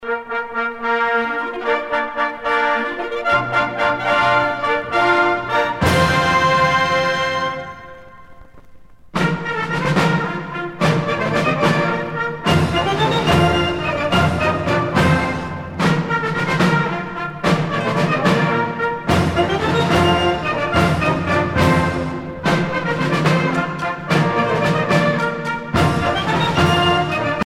militaire
Pièce musicale éditée